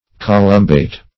Search Result for " columbate" : The Collaborative International Dictionary of English v.0.48: Columbate \Co*lum"bate\, n. [Cf. F. colombate.